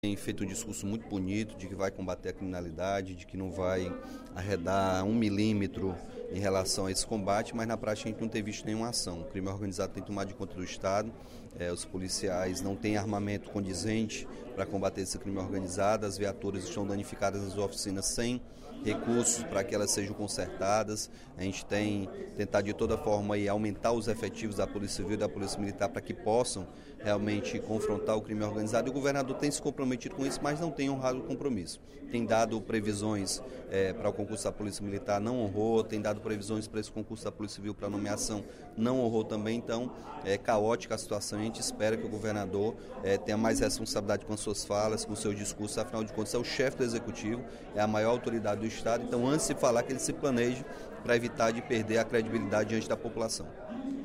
O deputado Capitão Wagner (PR) cobrou do governador Camilo Santana, no primeiro expediente da sessão plenária desta quarta-feira (06/07), a nomeação dos aprovados no último concurso da Polícia Civil.